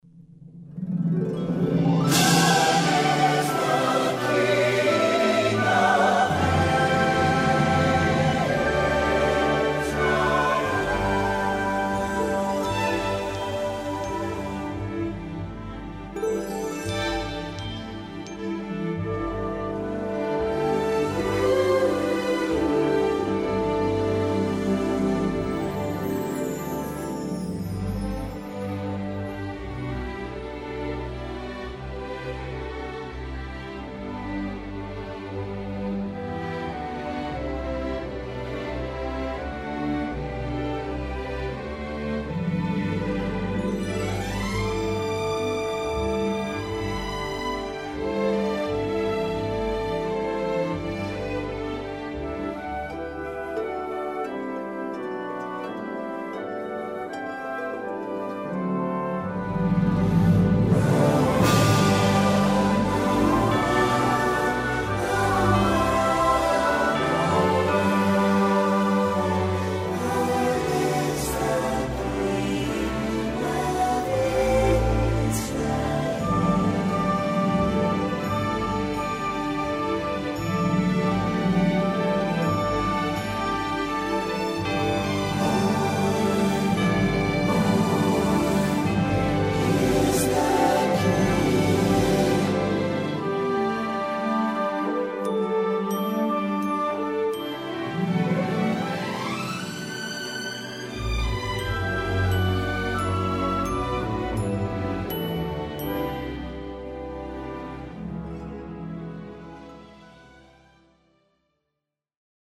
Original Key with BV